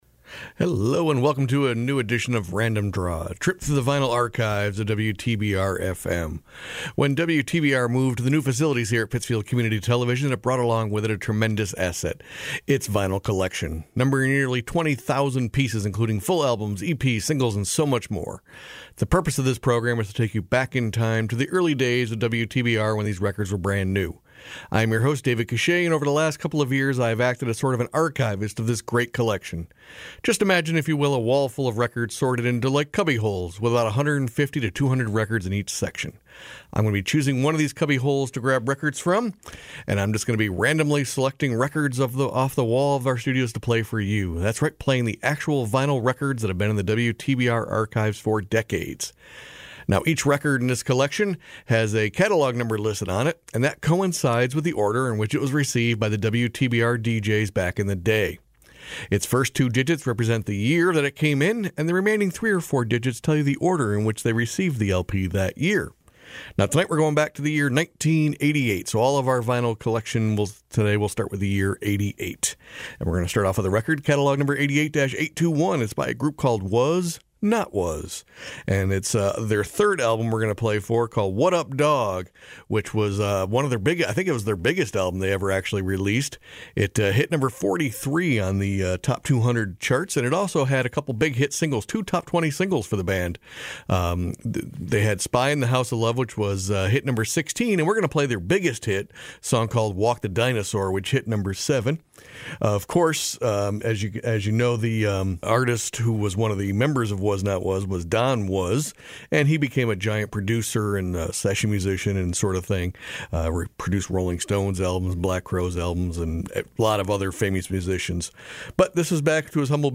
Random Draw can be heard every Saturday morning at 9am on WTBR.